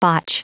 Prononciation du mot botch en anglais (fichier audio)
Prononciation du mot : botch